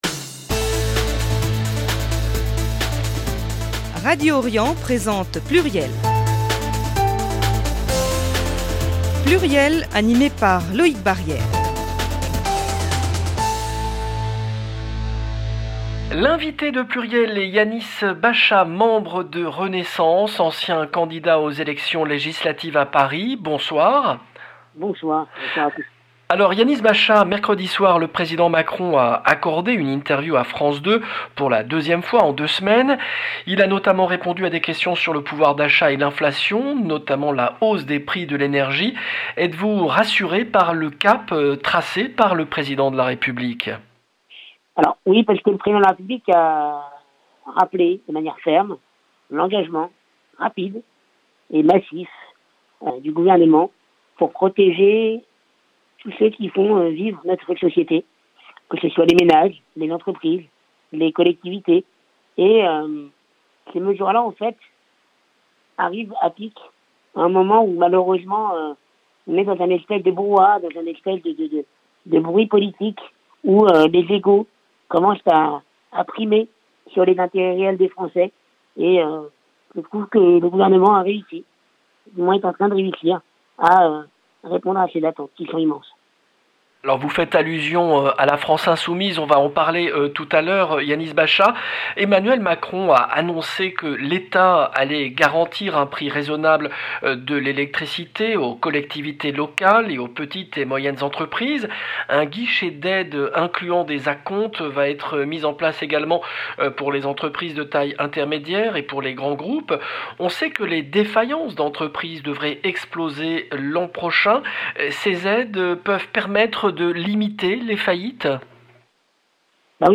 PLURIEL, le rendez-vous politique du vendredi 28 octobre 2022